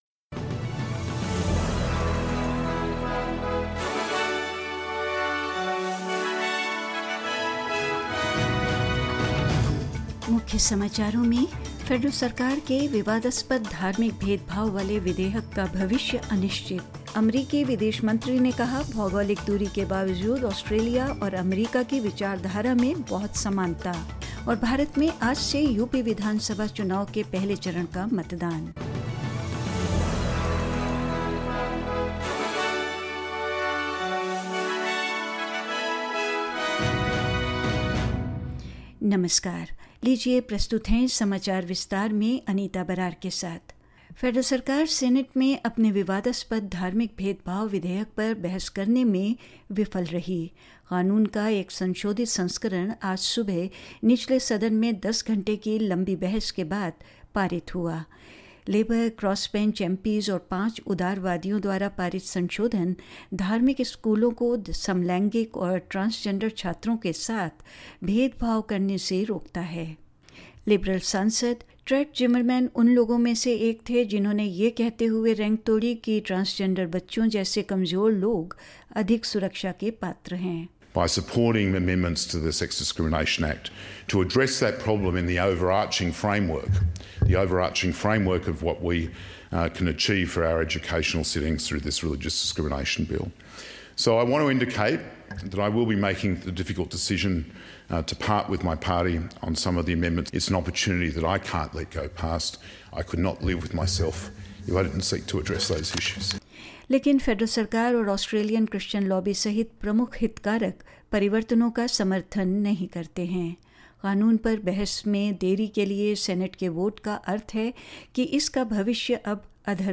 In this latest SBS Hindi bulletin: The future of the Federal Government's controversial religious discrimination bill hangs in the balance; The U-S Secretary of State Anthony Blinken says Australia and the United States have similar values and interests; In UP, India, the first phase of polling begins and more news.